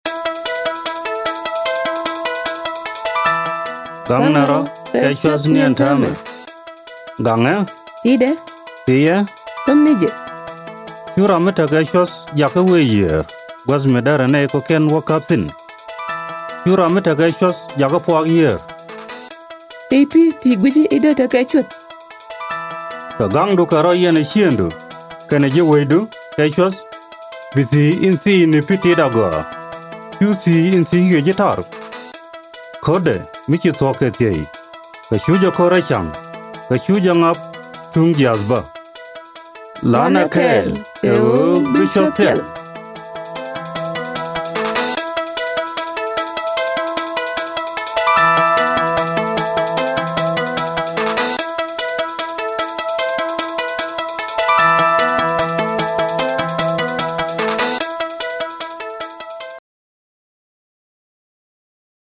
Guinea Worm Public Service Announcements (PSAs)
The Center's Guinea Worm Eradication Program and Office of Public Information developed 12 public service announcements to be read by native African speakers: first to be aired in English, Hausa, and French, followed by Arabic, Fulani, and Bambara.